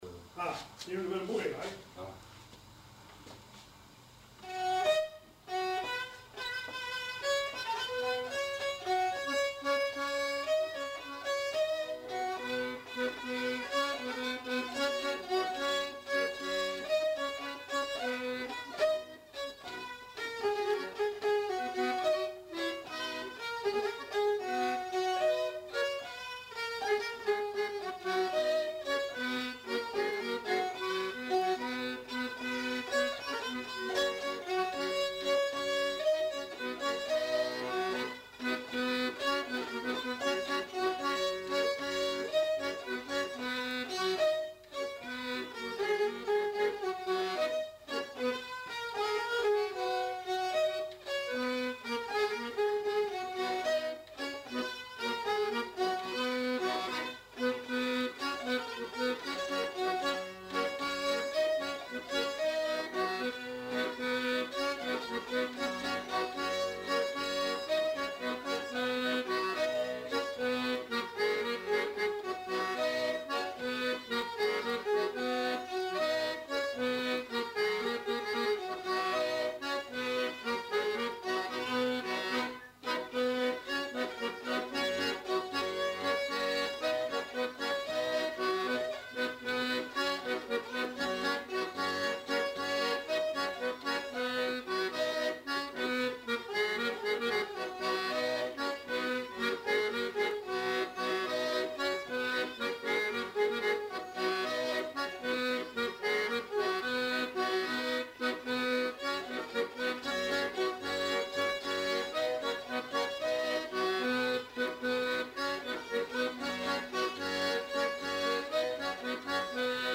Aire culturelle : Haut-Agenais
Lieu : Sainte-Livrade-sur-Lot
Genre : morceau instrumental
Instrument de musique : violon ; accordéon diatonique
Danse : bourrée